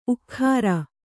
♪ ukkhāra